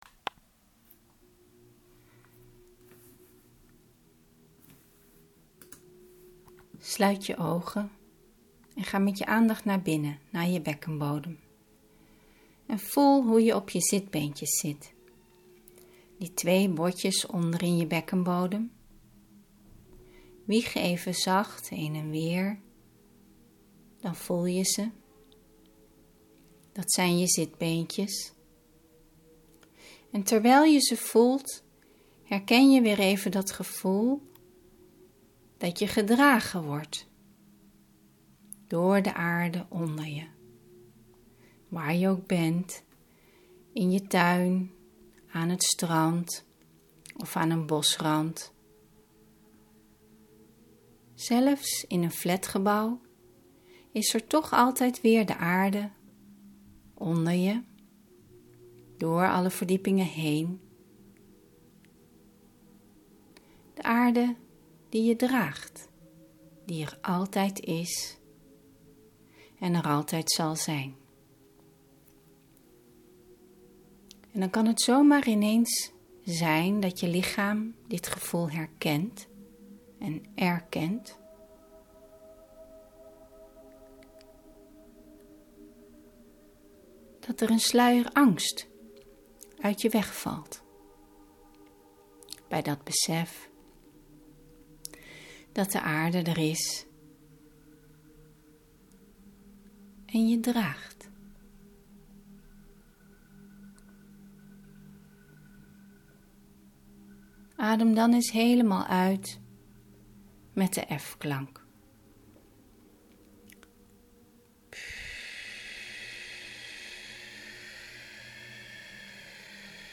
Extra content Dit is een zittende ademoefening om los te laten, die je in principe overal kunt doen: in de trein, op het perron en in je lunchpauze op een bankje.